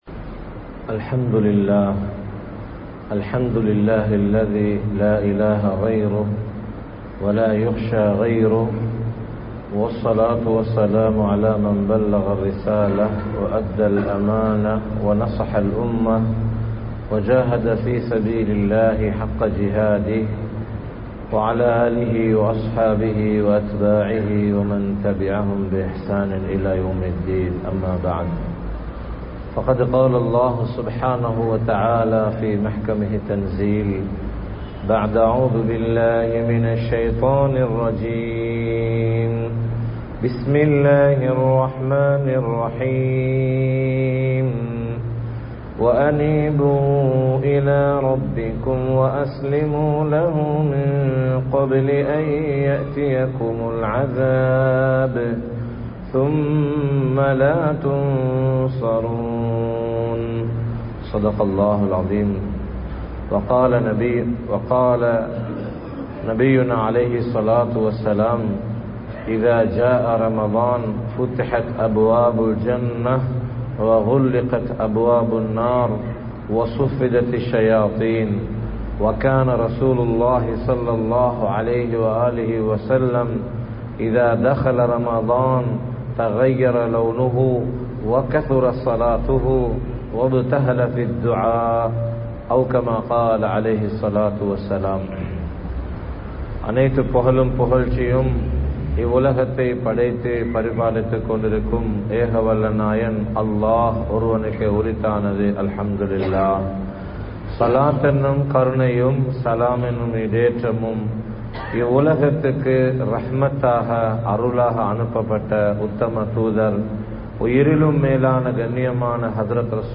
ரமழானை திட்டமிடுவோம் | Audio Bayans | All Ceylon Muslim Youth Community | Addalaichenai
Kurunegala, Mallawapitiya Jumua Masjidh